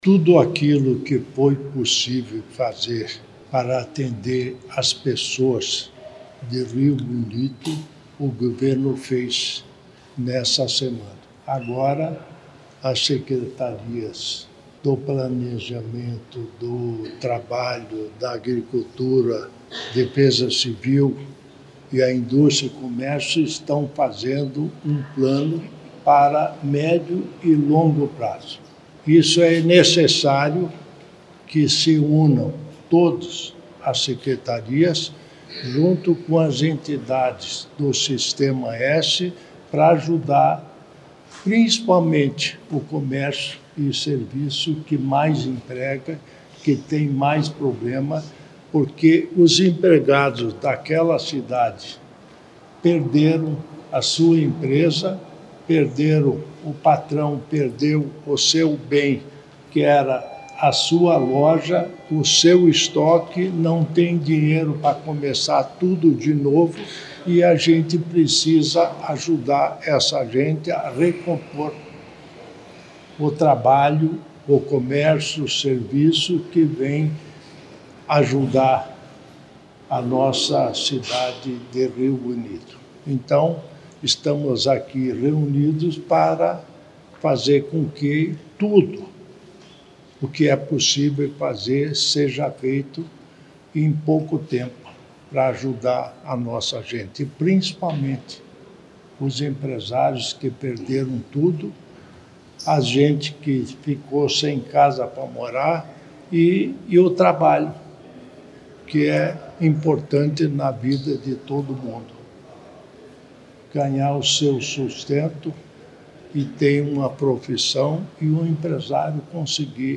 Sonora do vice-governador Darci Piana sobre o plano de retomada econômica para Rio Bonito do Iguaçu após tornado